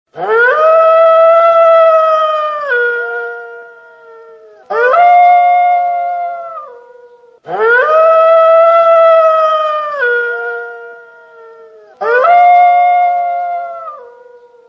Ringetone Ulv
Kategori Dyr